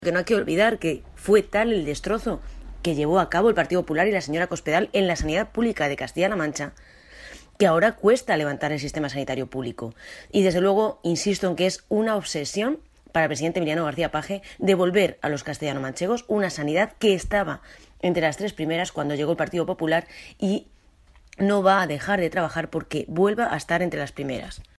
La diputada del Grupo Parlamentario Socialista en las Cortes de Castilla-La Mancha, Ana Isabel Abengózar, ha mostrado su satisfacción porque el "nuevo descenso en las listas de espera sanitarias" durante el pasado mes de julio.
Cortes de audio de la rueda de prensa